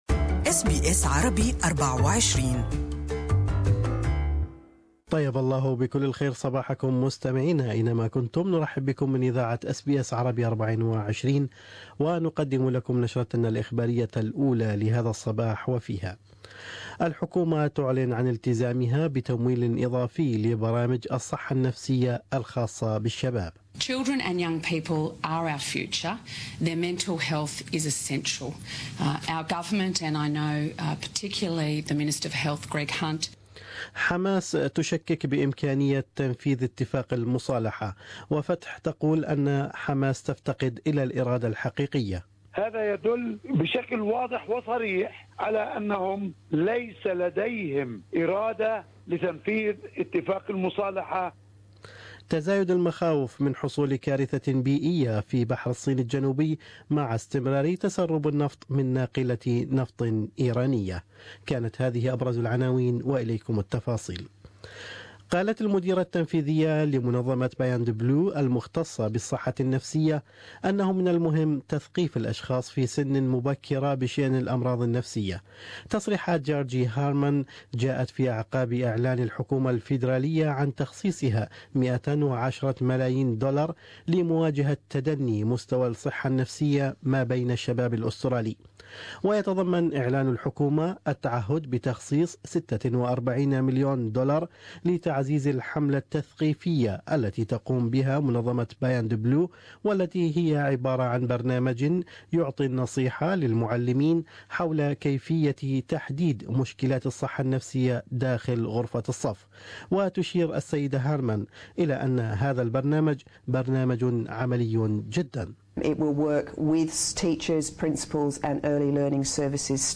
News Bulletin: Federal govt Pledges 110-million dollar package to fight anxiety and depression among young Australians